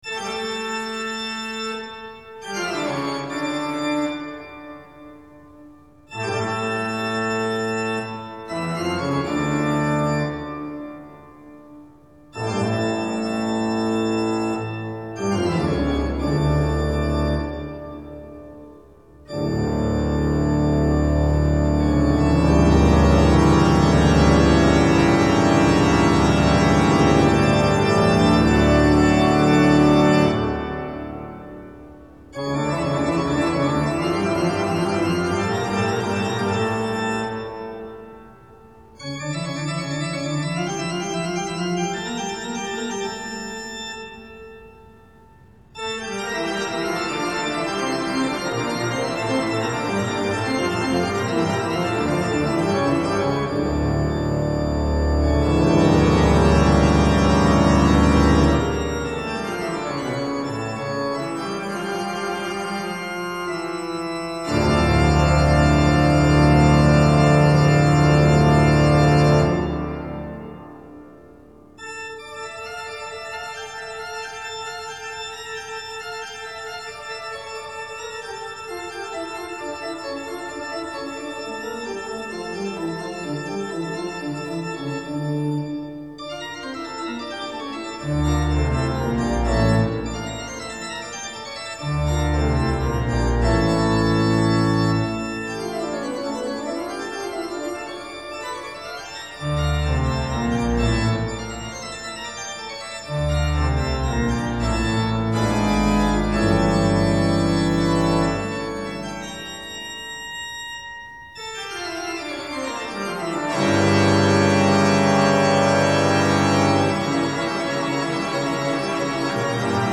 01-toccata-in-d.mp3